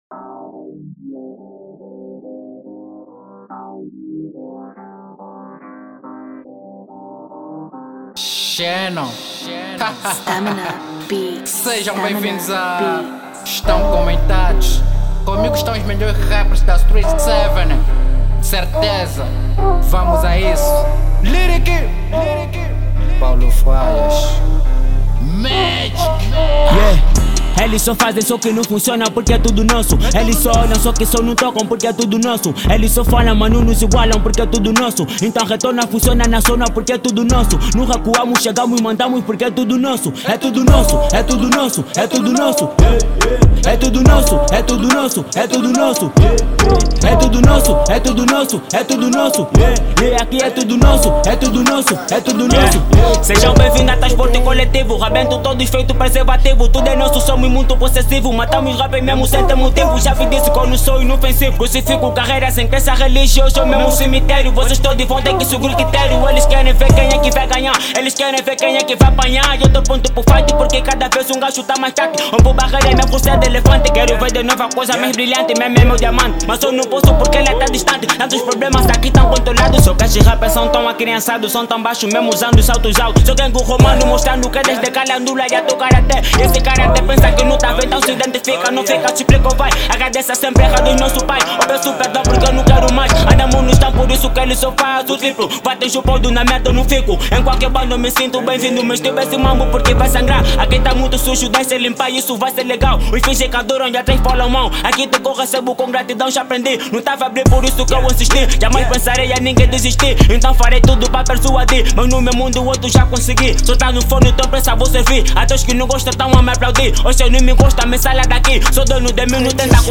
Estilo: Rap